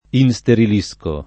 isterilire v.; isterilisco [iSteril&Sko], ‑sci — anche insterilire: insterilisco [